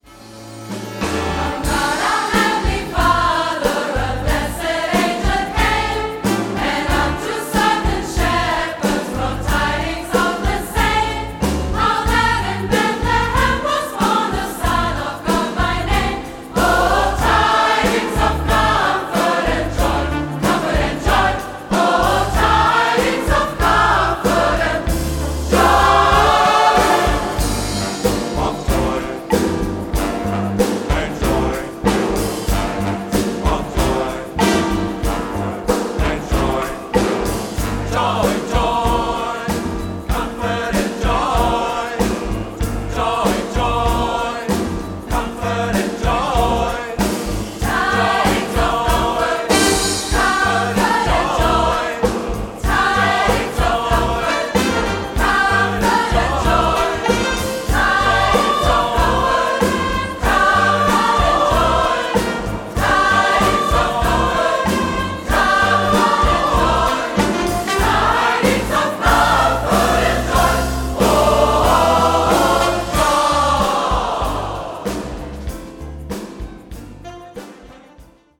• SATB + Piano